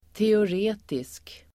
Uttal: [teor'e:tisk]